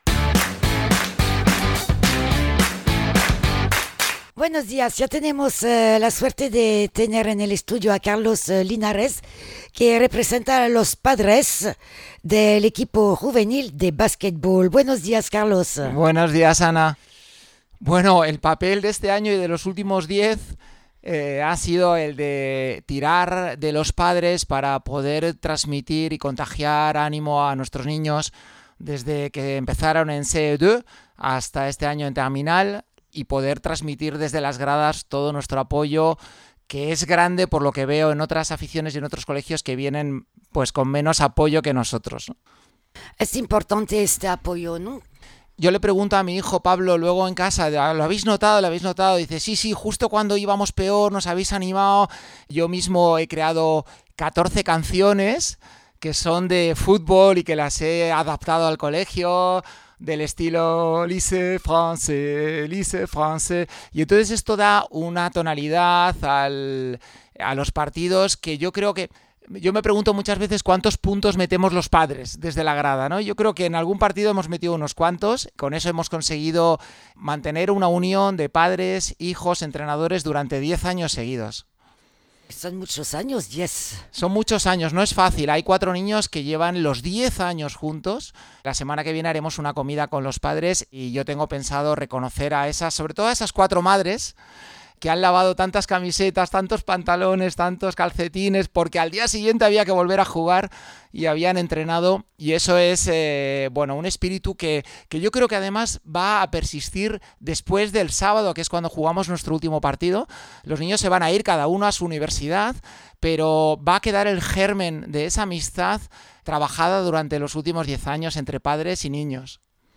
Nos cuenta esos 10 años acompañando al equipo juvenil durante todos los encuentros. Se puede escuchar también los mensajes de los padres para animar el equipo para el encuentro de mañana sabado 14 al LFB.